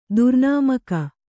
♪ durnāmaka